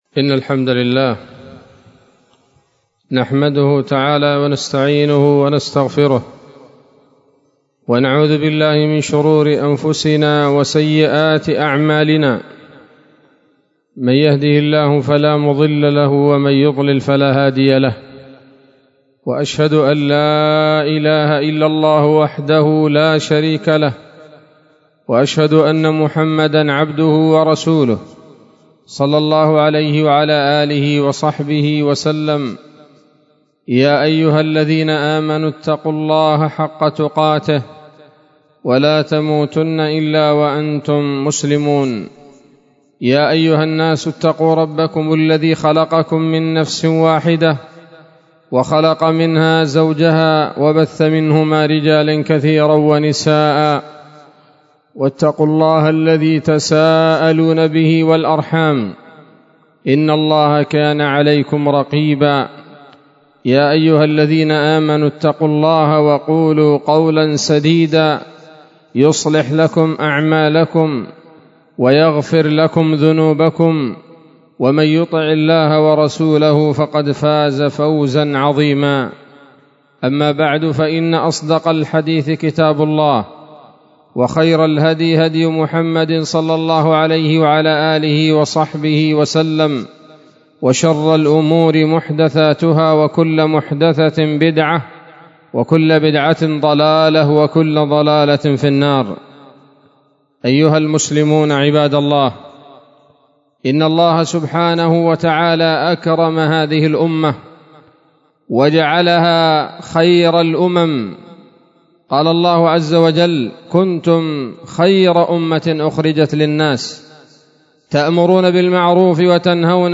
خطبة جمعة بعنوان: (( بدعة المولد النبوي )) 11 ربيع أول 1444 هـ، دار الحديث السلفية بصلاح الدين